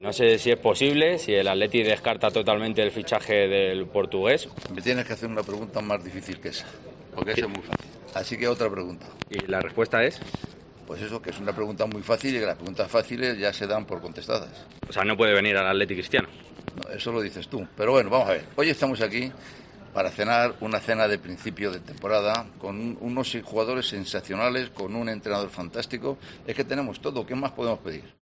AUDIO: El presidente del Atlético de Madrid ha atendido a los medios en los prolegómenos de la cena de la plantilla en Segovia.